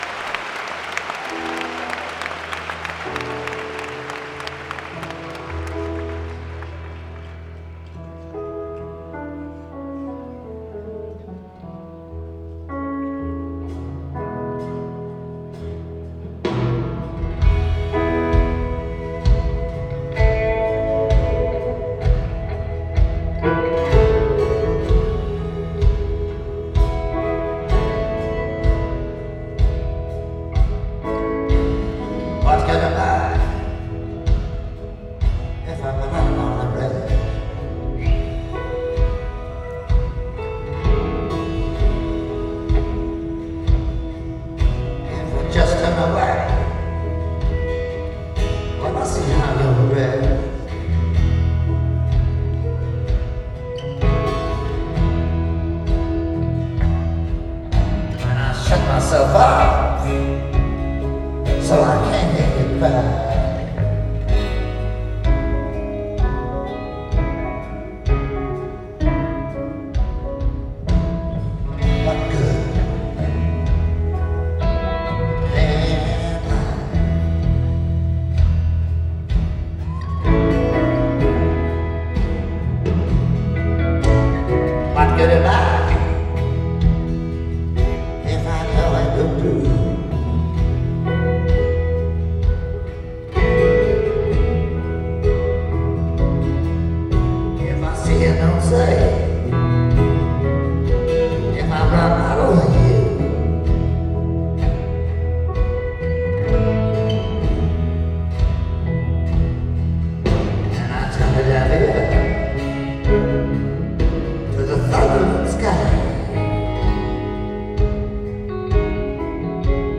Oslo Spektrum - Oslo, Norway